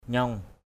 /ɲɔŋ/ (đg.) co lại, thun lại. aw nyaong tagok a| _v” t_gK áo thun lại.